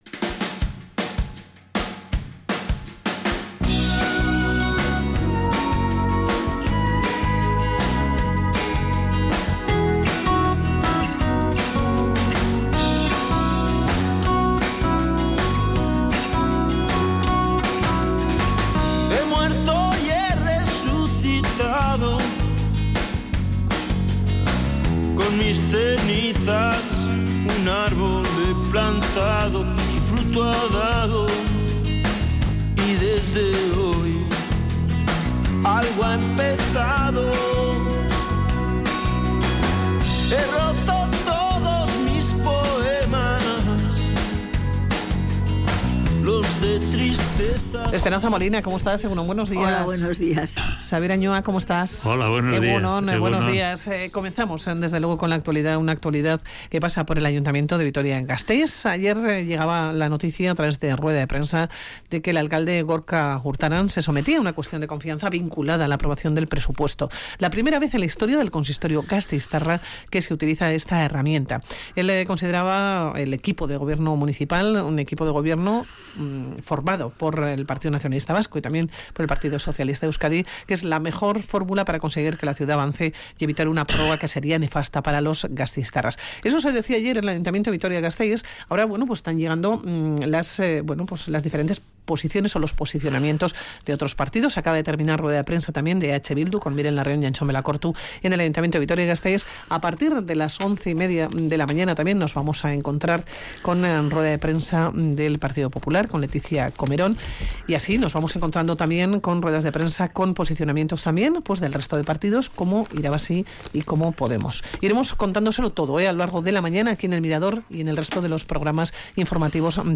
Audio: Hoy en la tertulia de sabios: la subida de la luz, bono social, la cuestión de confianza en el ayuntamiento de Vitoria y Trump.